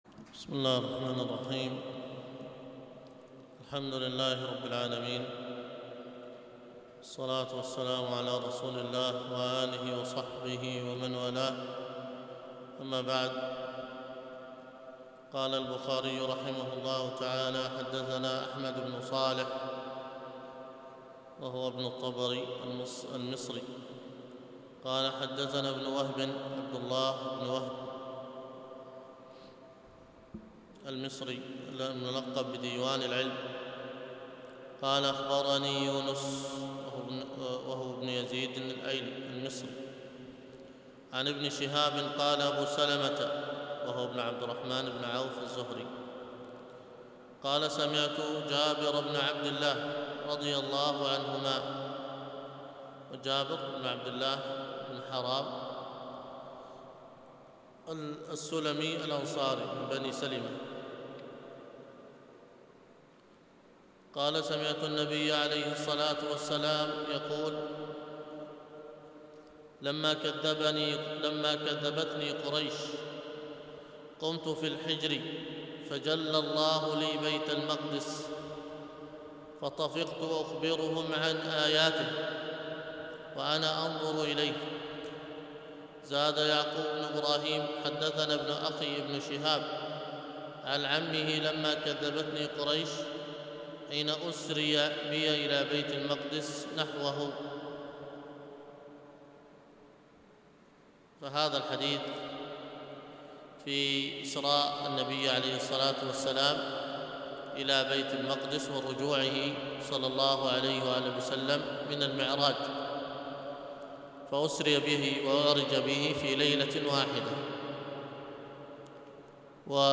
الدرس في جناية القبورية - الجزء الأول، وكانت بعد المغرب من يوم الأحد 13 محرم 1431هـ